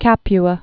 (kăpy-ə, käpwä)